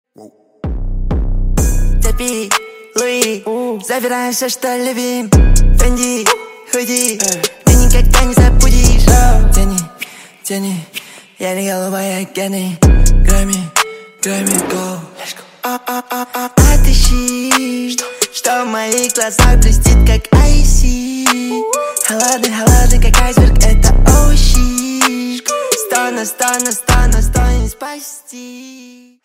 Громкие Рингтоны С Басами
Рэп Хип-Хоп Рингтоны